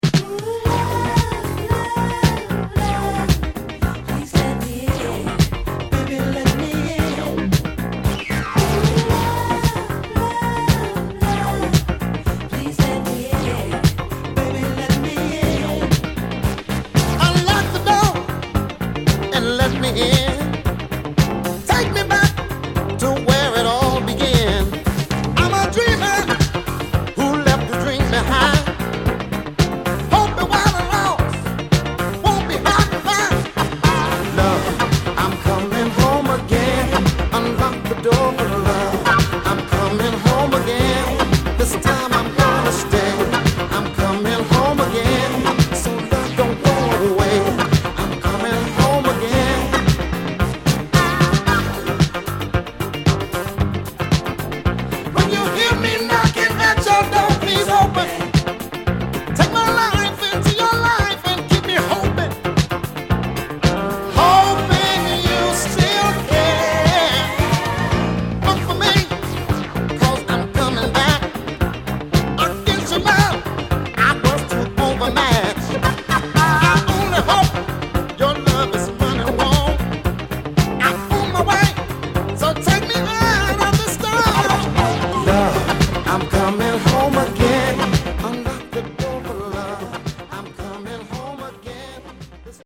全体に期待を裏切らない軽やかなディスコアルバムで